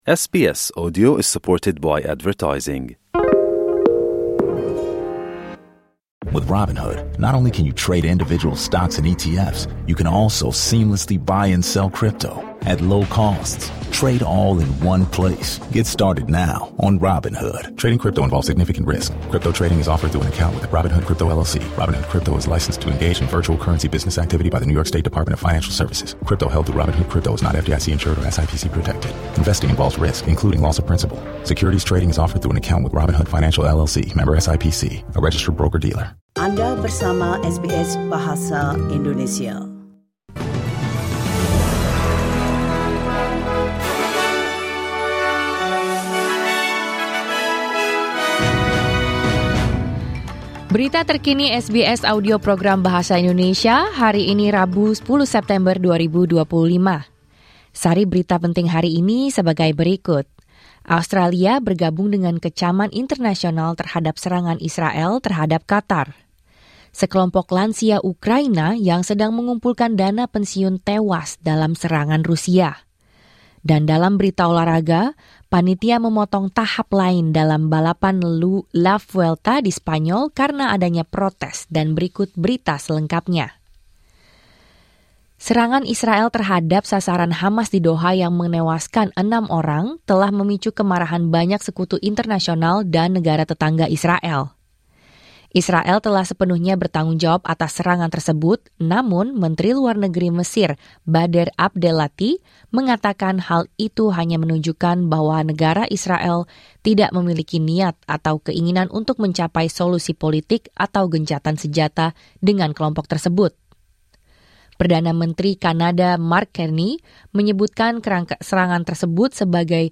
Berita Terkini SBS Audio Program Bahasa Indonesia - 10 September 2025